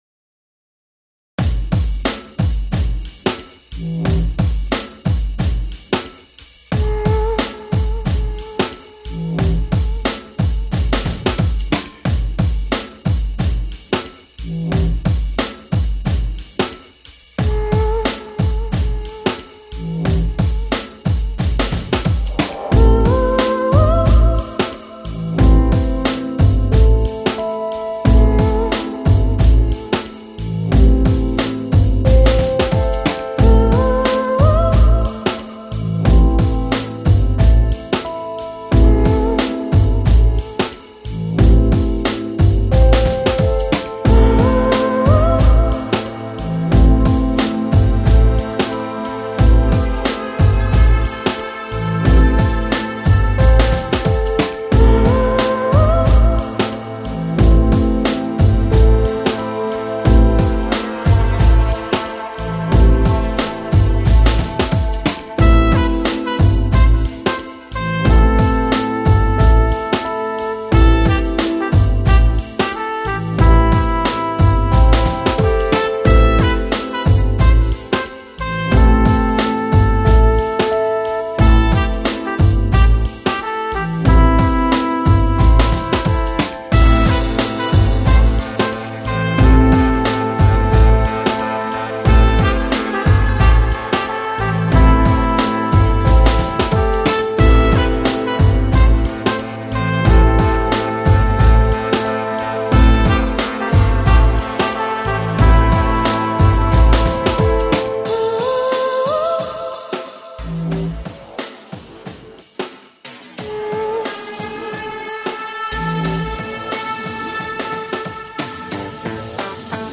Жанр: Chill Out, Lo-Fi, ChillHop